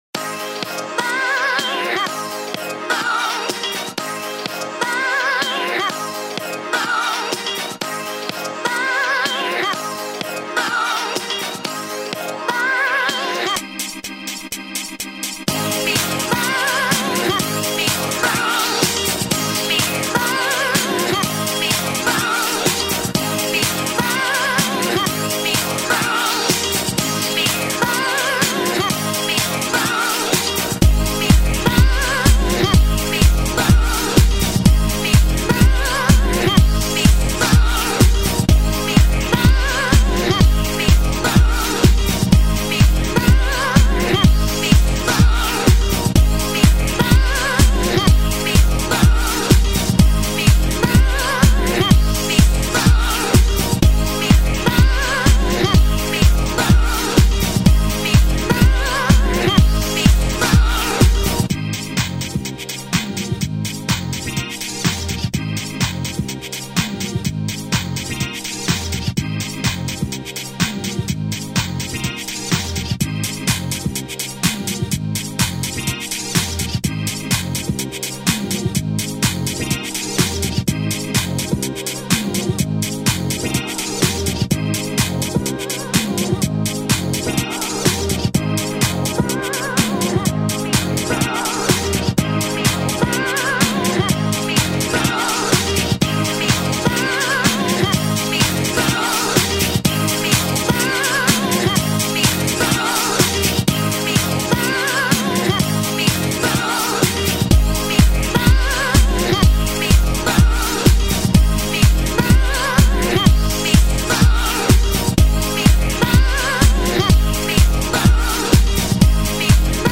디스코느낌의